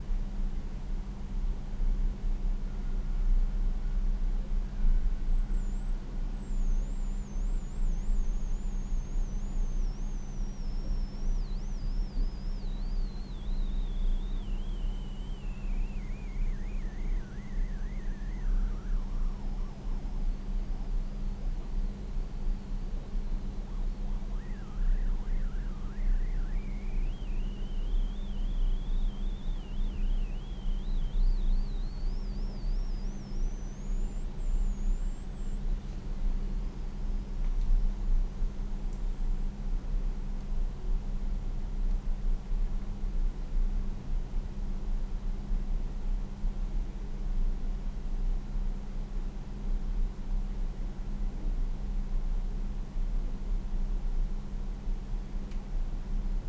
Странный шум у JoeMeek 47A
Всем привет Заметил у своего микрофона странный звук. гудит какая-то одна частота, глиссандо сверху вниз и потом обратно.